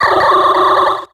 Grito de Gorebyss.ogg
Grito_de_Gorebyss.ogg.mp3